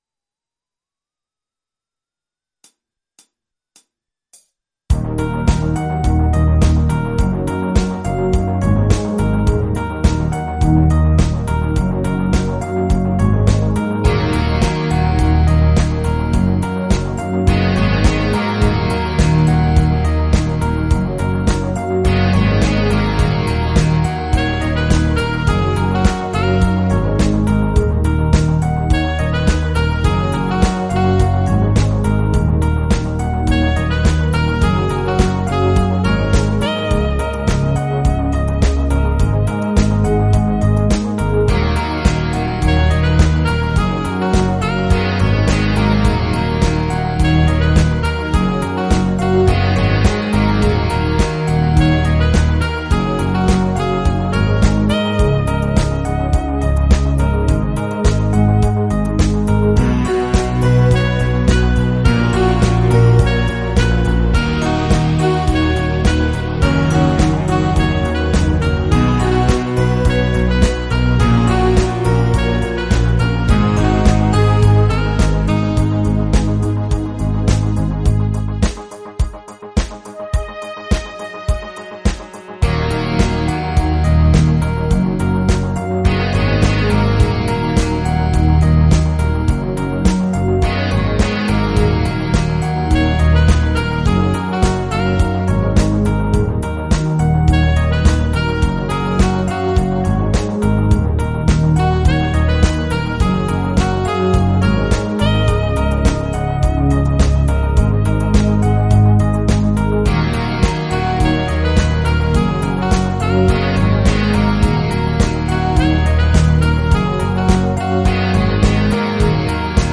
versione strumentale multitraccia